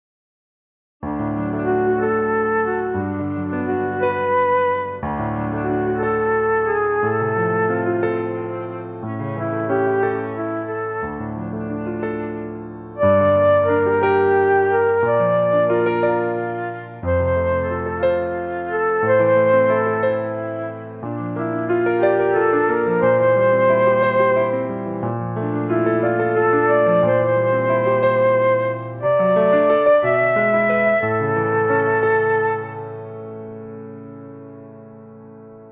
lecture chantée - complet